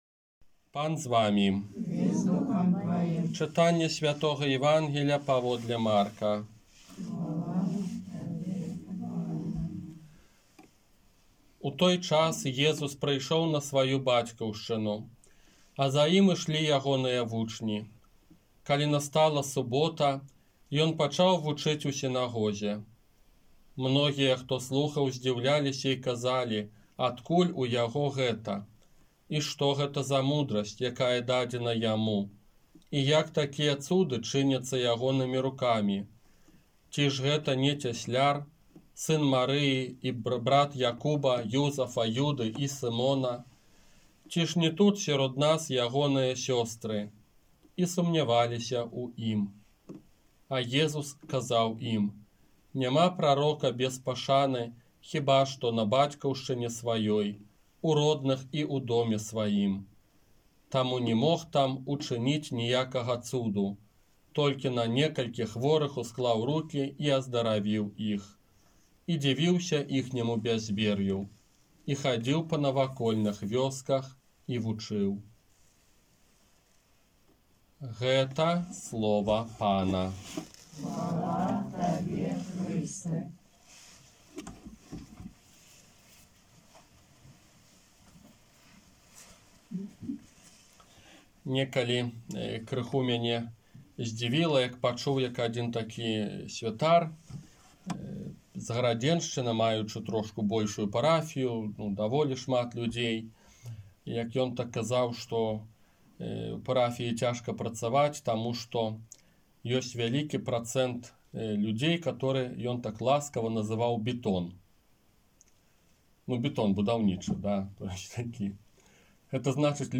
ОРША - ПАРАФІЯ СВЯТОГА ЯЗЭПА
Казанне на чатырнаццатую звычайную нядзелю 4 ліпеня 2021 года